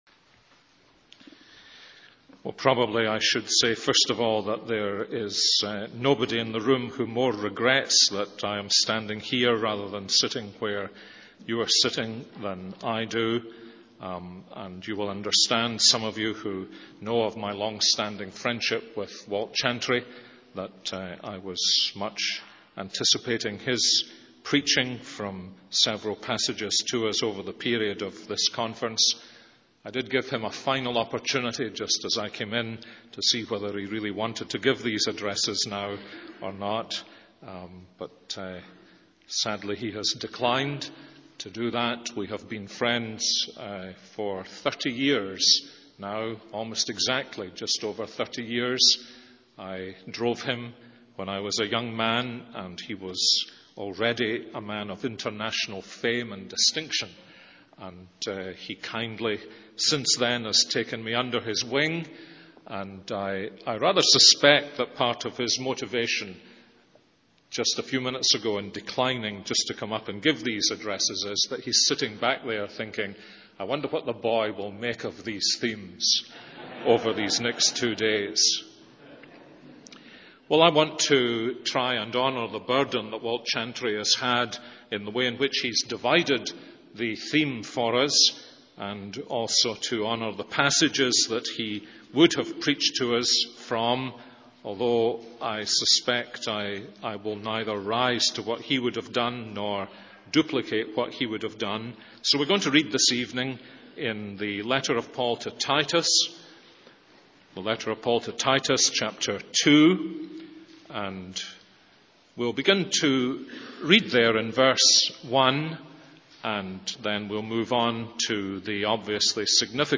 In this sermon, the speaker reflects on his friendship with a renowned preacher and the influence he has had on his own life. He then turns to the letter of Paul to Titus and focuses on the theme of holiness. The speaker emphasizes that holiness is God's will for believers and is a result of the grace of God appearing in the person of Jesus Christ.